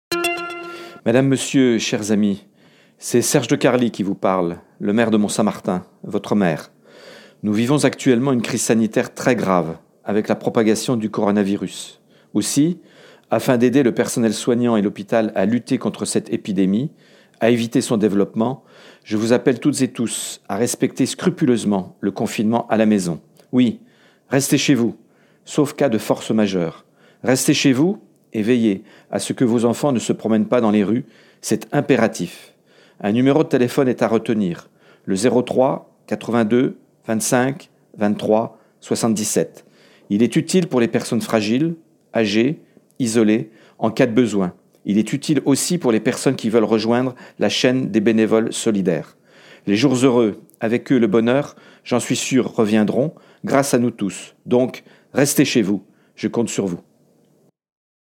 Un véhicule municipal , équipé d’une sono, sillonnera dès aujourd’hui les différentes rues, différents quartiers de la ville, et diffusera un message du Maire, Monsieur Serge De Carli appelant chacune, chacun à respecter scrupuleusement les consignes sanitaires, en particulier celle du confinement pour soi-même mais aussi pour ses enfants.